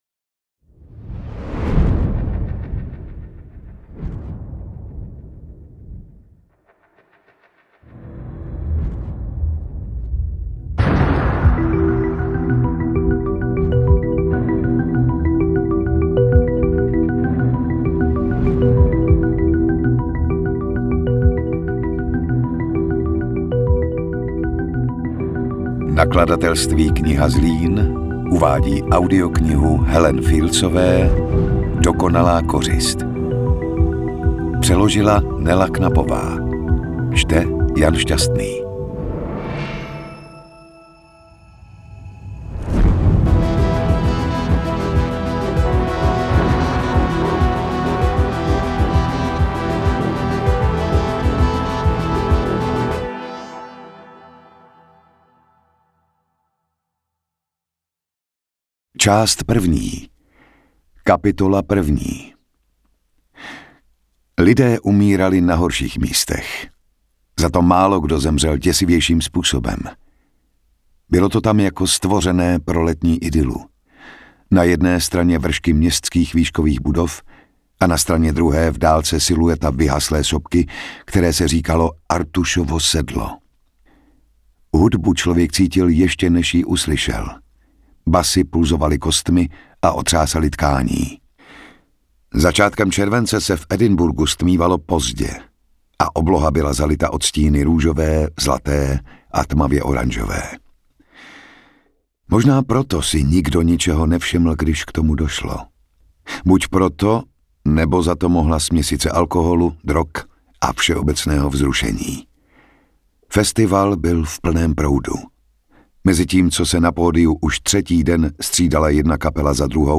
AudioKniha ke stažení, 71 x mp3, délka 16 hod. 3 min., velikost 1289,0 MB, česky